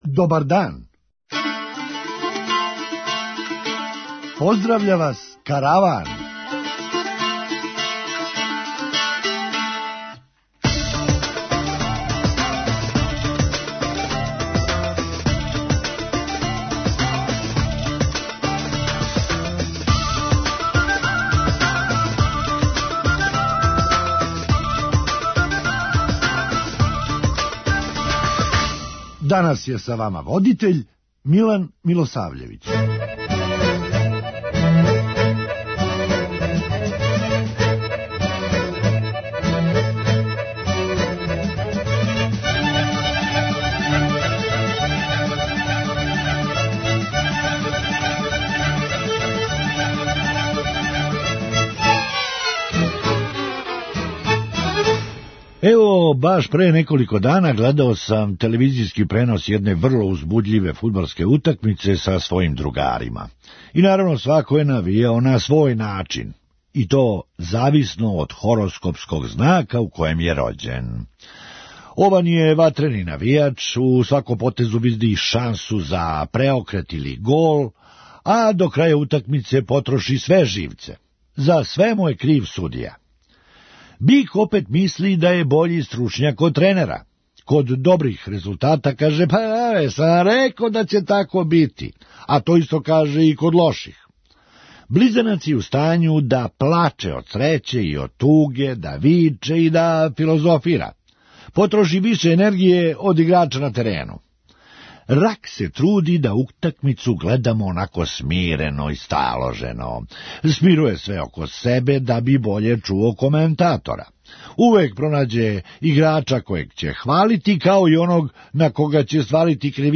Хумористичка емисија
Не знам шта може овај астролог да погоди, али знам сигурно да није погодио датум јер 26. фебруар није била субота већ петак! преузми : 8.99 MB Караван Autor: Забавна редакција Радио Бeограда 1 Караван се креће ка својој дестинацији већ више од 50 година, увек добро натоварен актуелним хумором и изворним народним песмама.